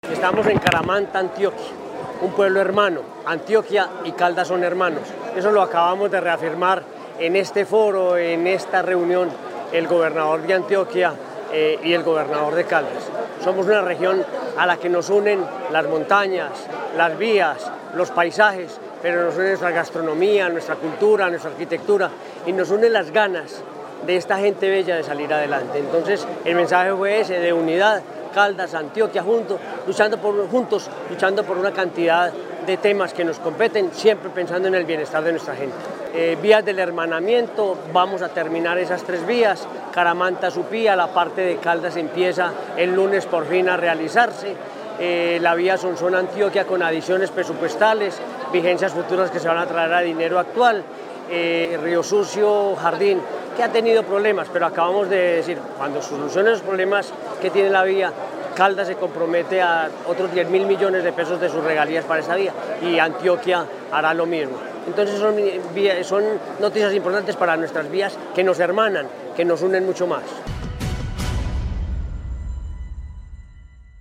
Henry Gutiérrez Ángel, gobernador de Caldas